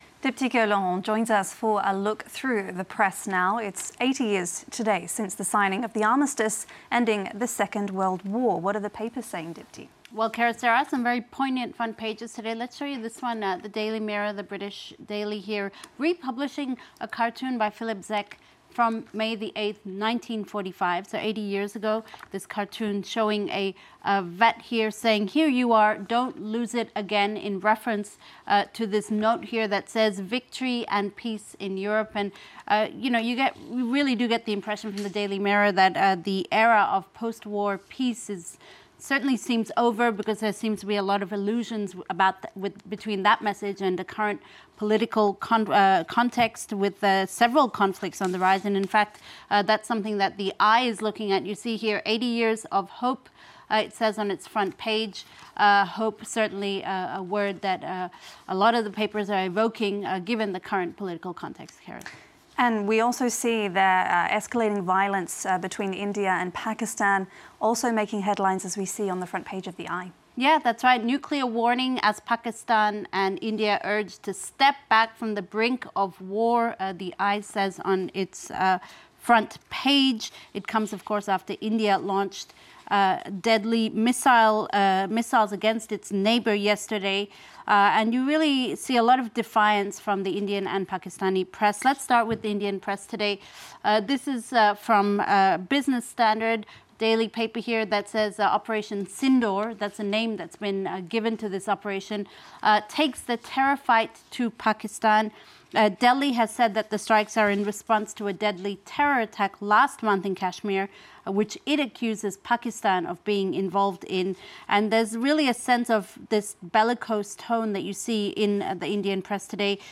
PRESS REVIEW – Thursday, May 8: We look at reactions from the Indian and Pakistani press after India's retaliatory strikes against its neighbour. How far will the nuclear powers go?